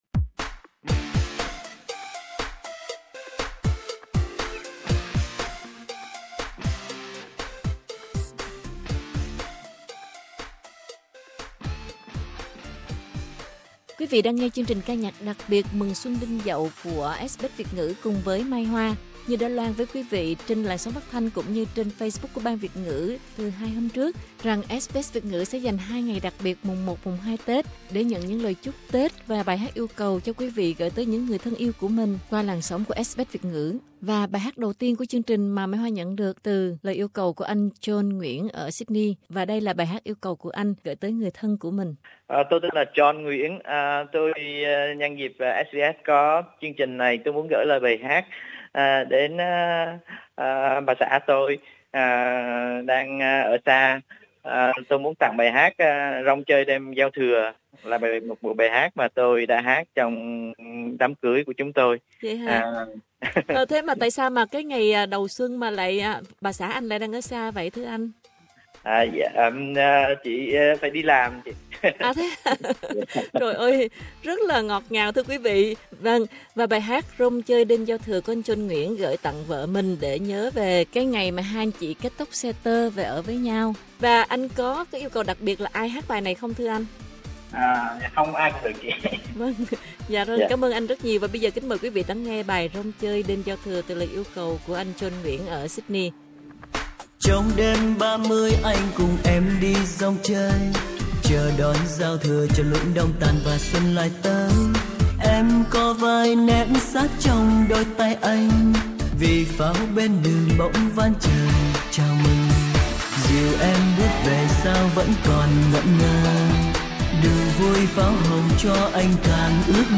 Chương trình ca nhạc đặc biệt